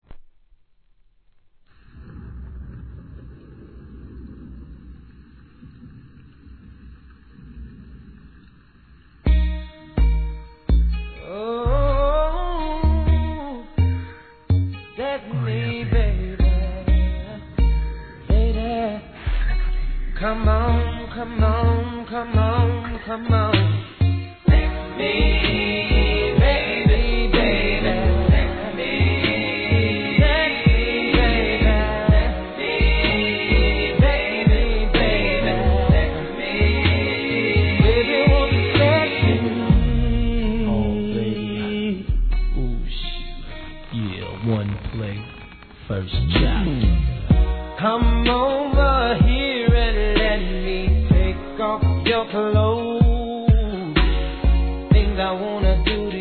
1. HIP HOP/R&B
スロウでムーディー、甘〜いセレクトで今回もバカ売れ確実！！